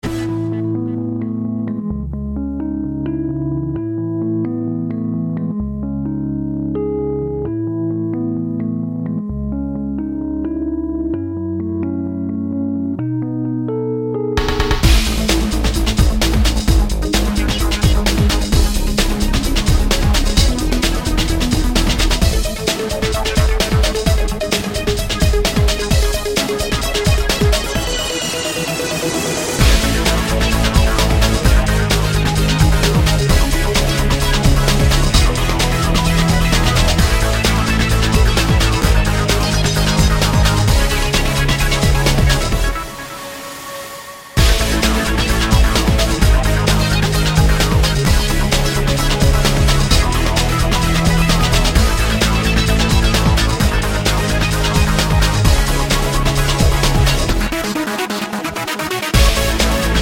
End Cut Down Christmas 2:45 Buy £1.50